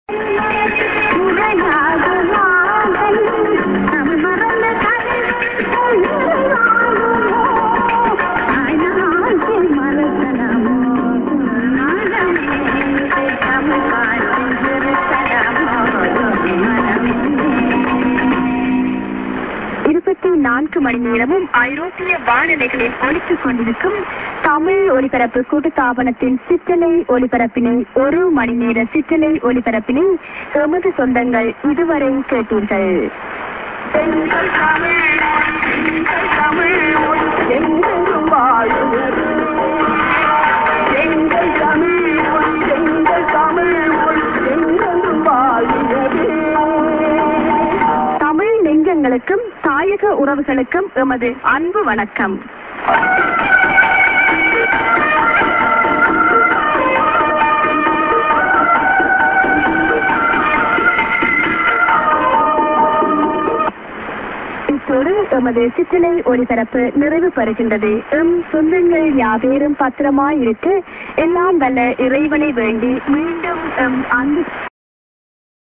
via DTK Julich end music->00'50":ID(women)-> s/off